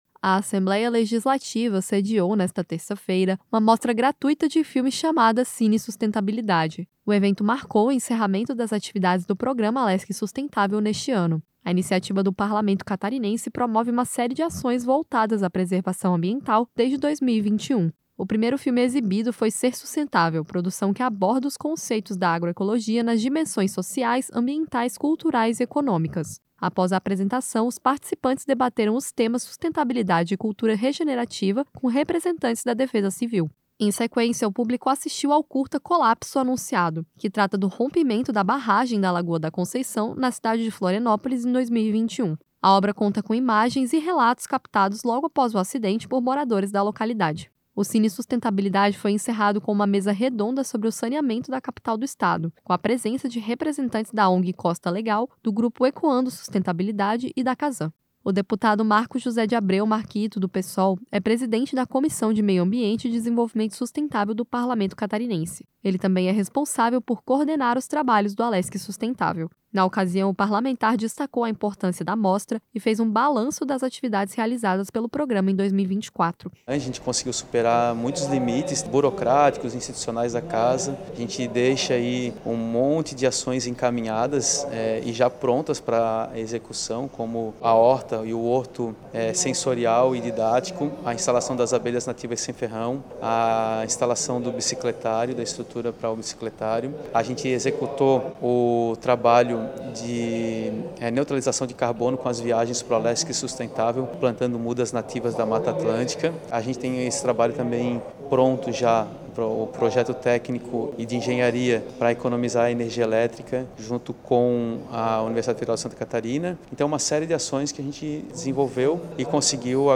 Entrevista com:
- deputado Marcos José de Abreu - Marquito (Psol), presidente da Comissão de Meio Ambiente e Desenvolvimento Sustentável.